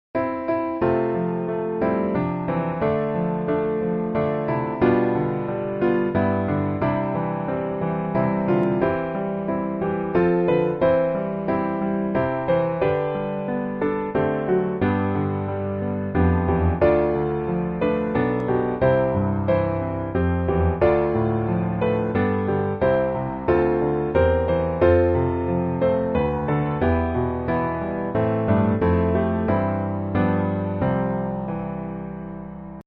C Majeur